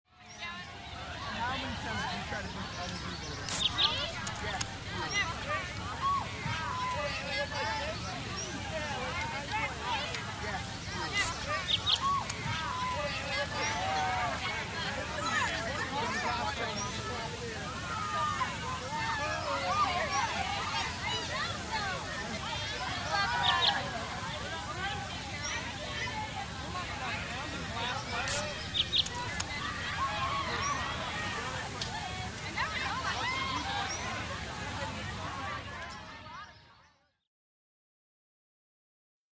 Exterior Barbecue Party Walla, Beer Cans Open And Grilling In Background.